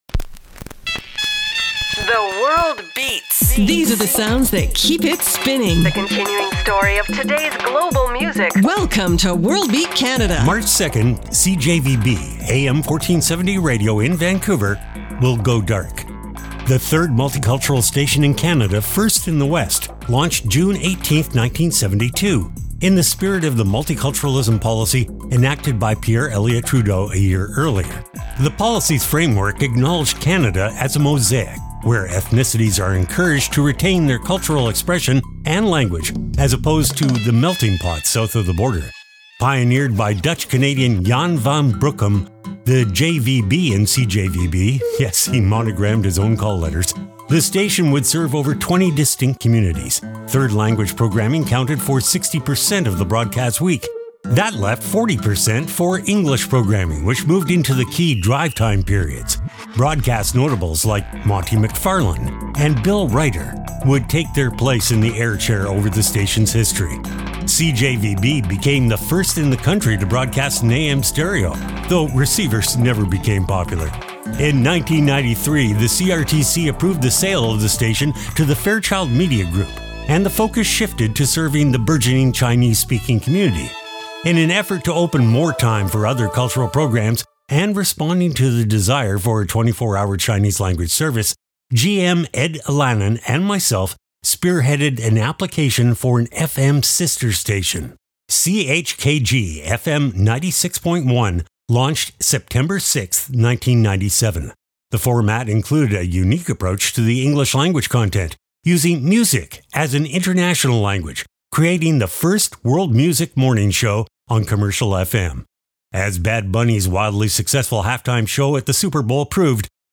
exciting global music alternative to jukebox radio
Weekly Program